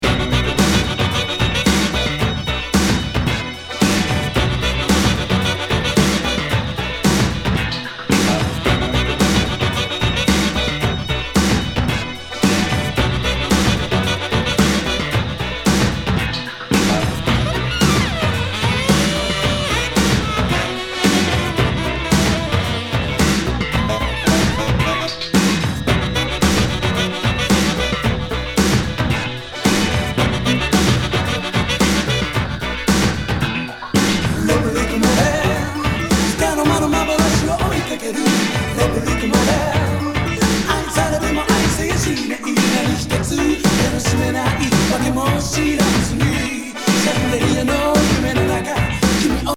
ダンスMIX!!